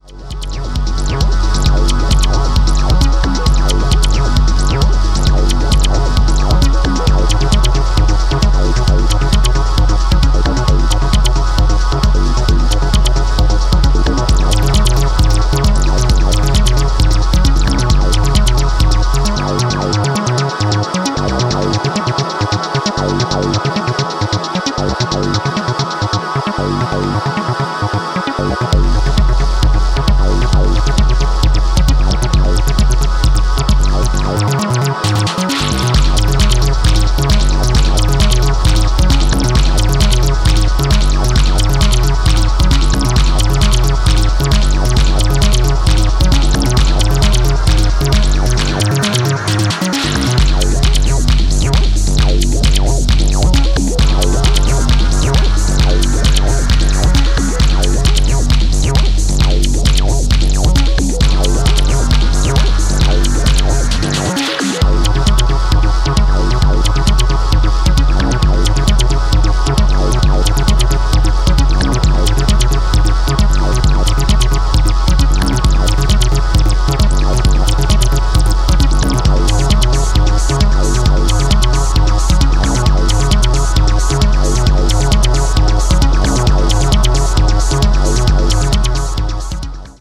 Electro